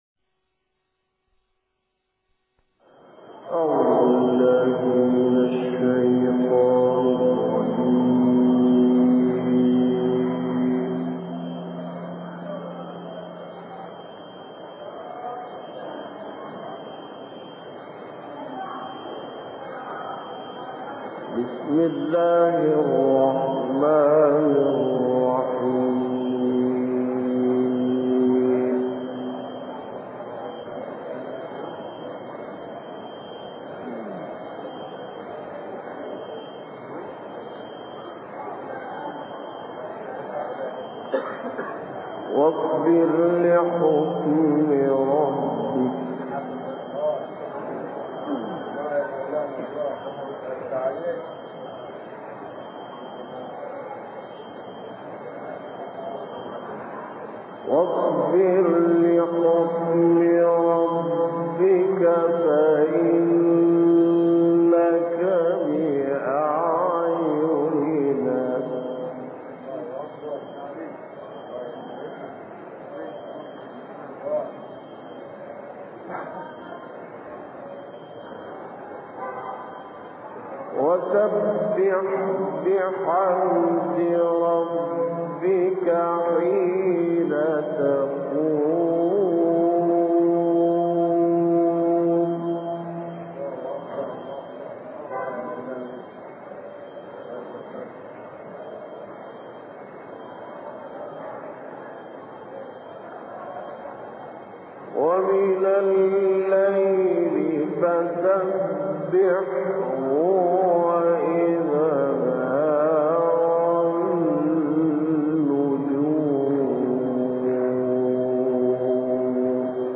تلاوت قرآن کريم